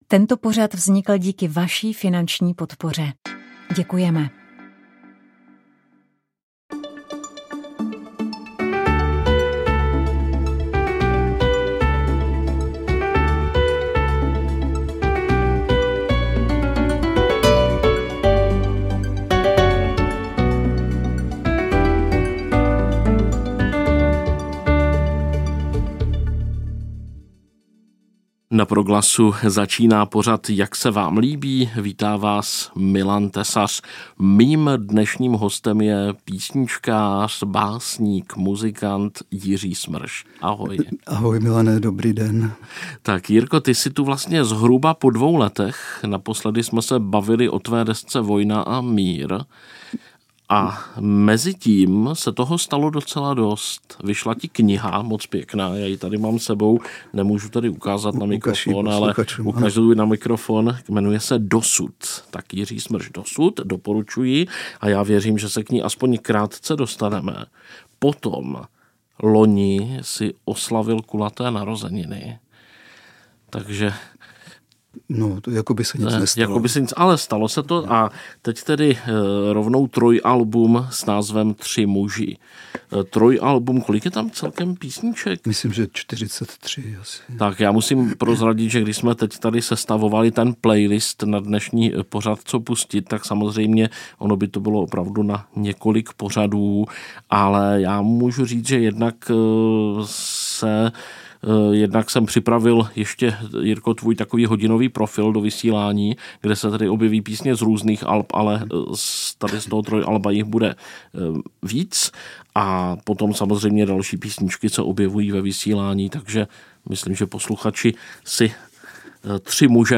živě z Koncertního studia